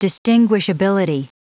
Prononciation du mot distinguishability en anglais (fichier audio)
Prononciation du mot : distinguishability
distinguishability.wav